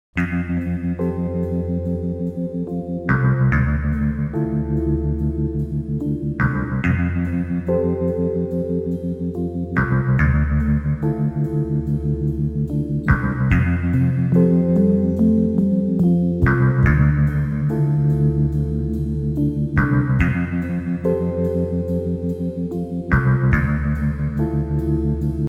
• Качество: 320, Stereo
гитара
без слов
инструментальные
саундтрек к фильму